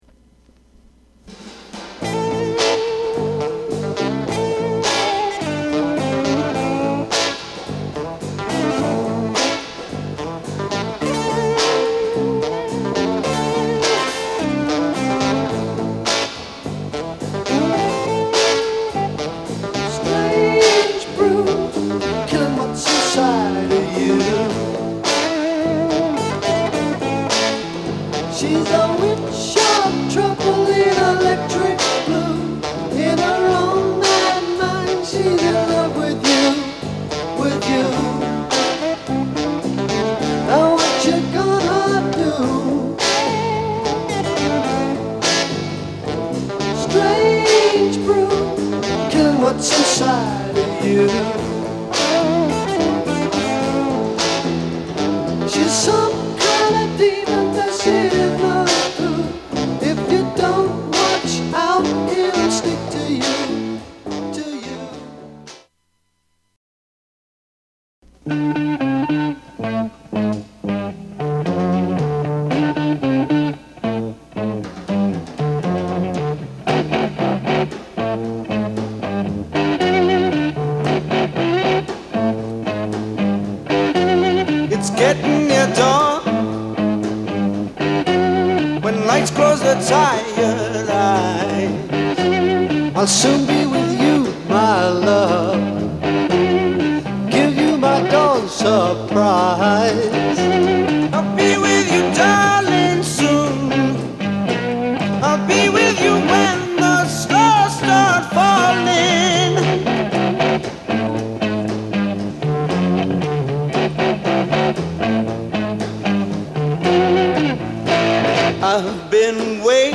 ROCK / BRITISH ROCK / BLUES
盤は薄い擦れや僅かですが音に影響がある傷がいくつかある、使用感が感じられる状態です。
ブルースをベースにしながら、ブリティッシュ・ロックという新たなスタイルを構築した時代のマスターピースと呼べる一枚。